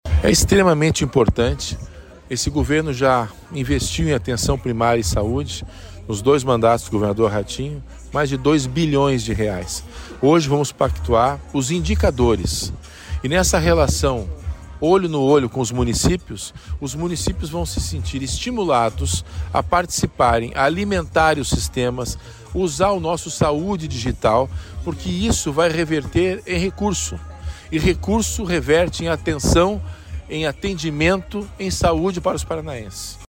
Sonora do secretário da Saúde em exercício, César Neves, sobre a 6ª Reunião Ordinária da Comissão Intergestores Bipartite